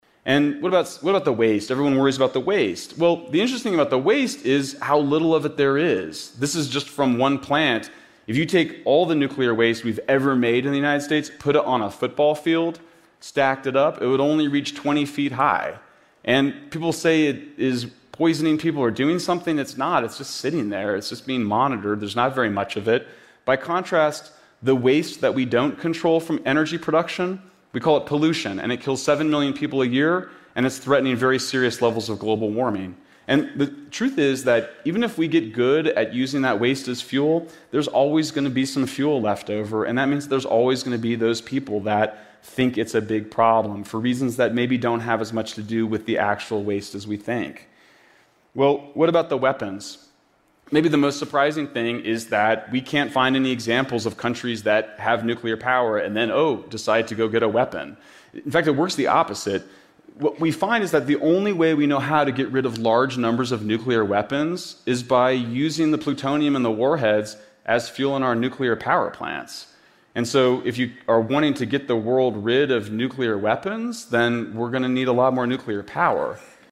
TED演讲:恐惧核能是怎样伤害环境的(9) 听力文件下载—在线英语听力室